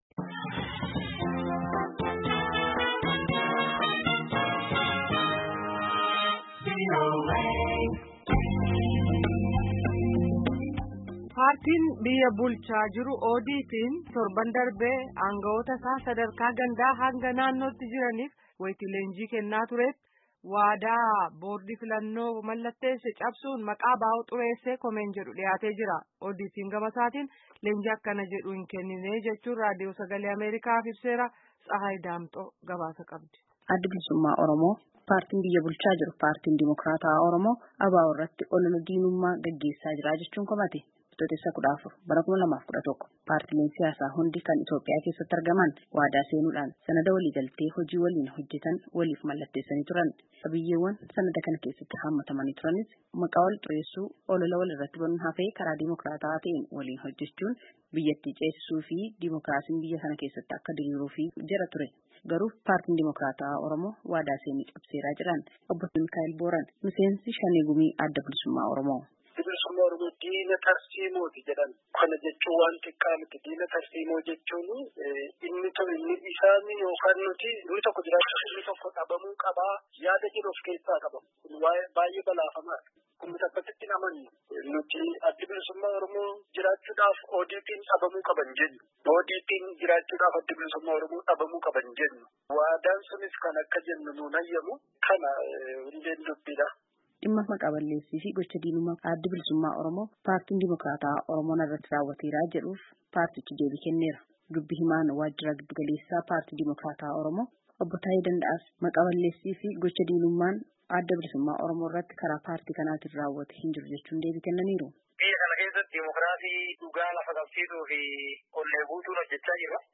Gabaasa